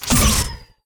weapon_laser_005.wav